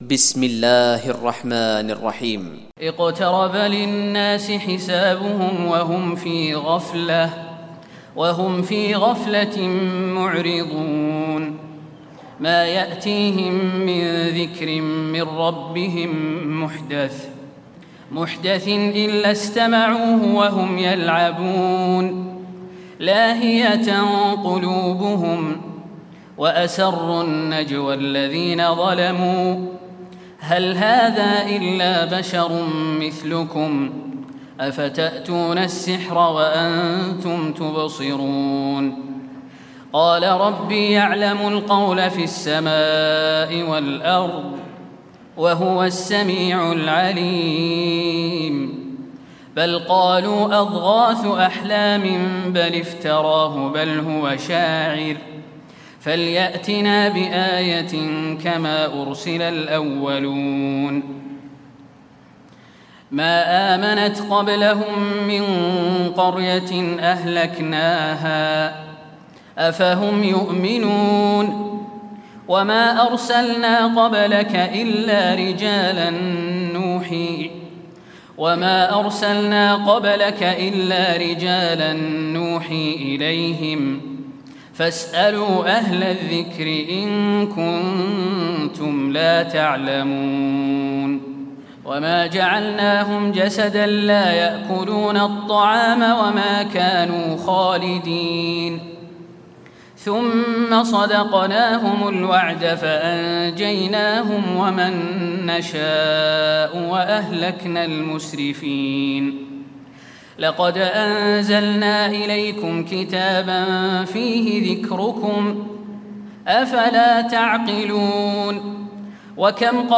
تراويح الليلة السادسة عشر رمضان 1436هـ سورة الأنبياء كاملة Taraweeh 16 st night Ramadan 1436H from Surah Al-Anbiyaa > تراويح الحرم النبوي عام 1436 🕌 > التراويح - تلاوات الحرمين